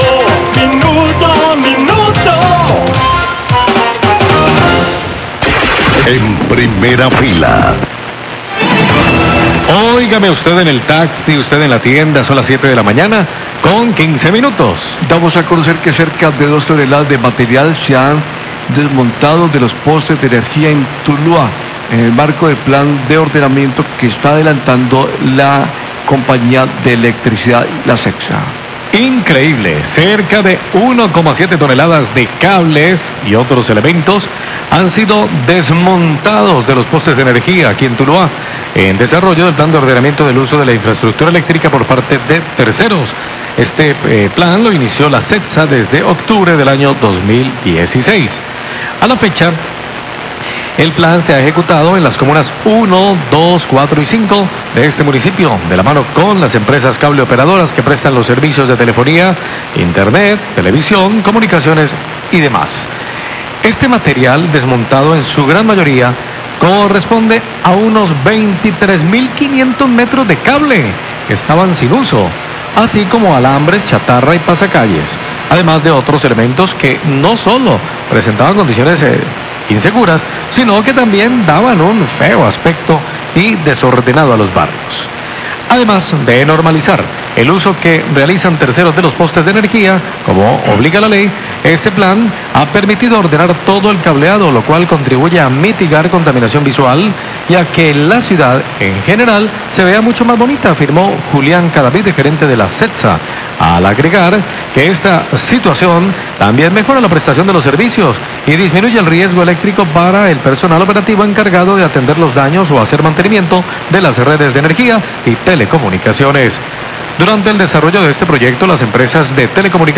Radio
comunicado de prensa